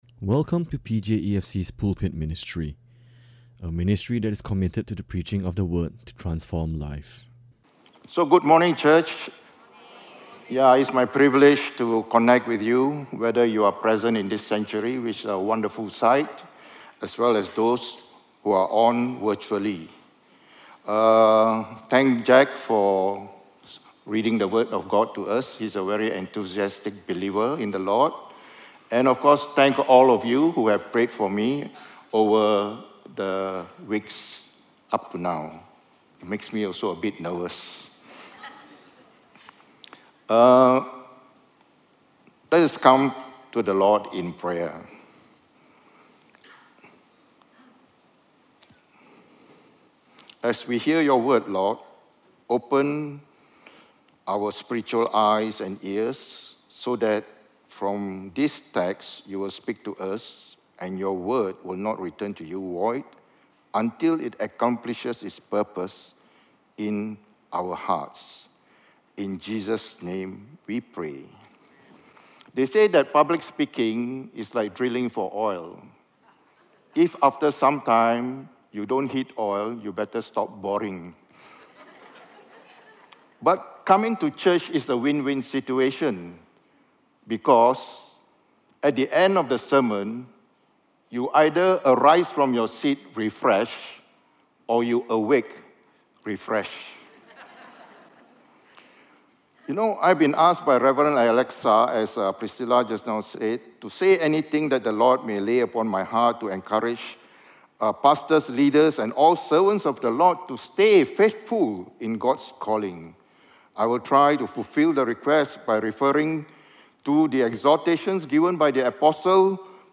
This is a stand-alone sermon.